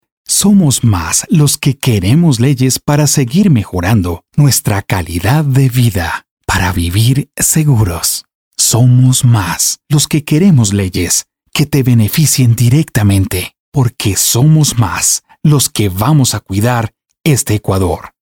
I own a professional recording studio and I have several recording microphones such U-47, Sm7B and more.
Español male Spanish voiceover voice over locutor voice actor video audio corporativo jingles masculino voz
kolumbianisch
Sprechprobe: Sonstiges (Muttersprache):